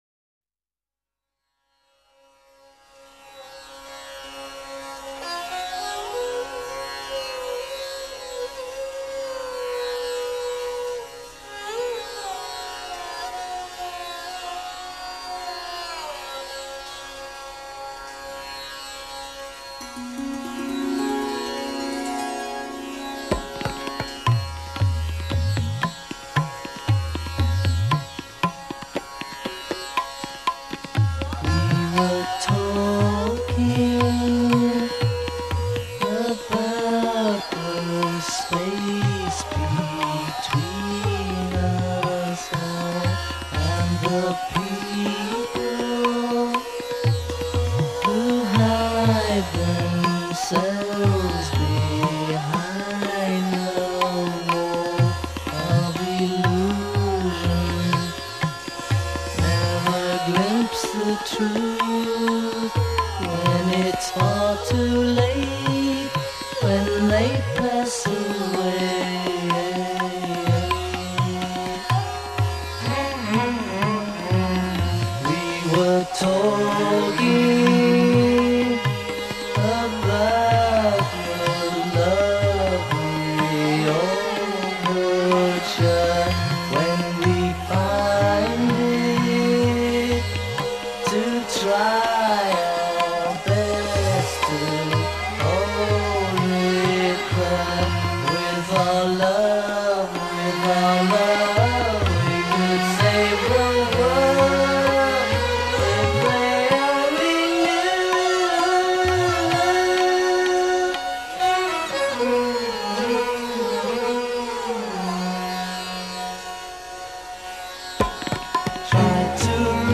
lead vocals, sitar, guitar and tambura